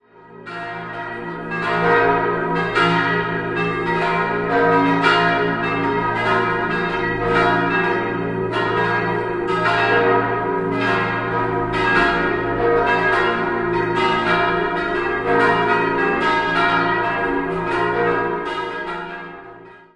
5-stimmiges Westminster-Geläute: b°-es'-f'-g'-b' Die Glocken 1, 3, 4 und 5 wurden 1948/1949 von Karl Czudnochowsky in Erding gegossen.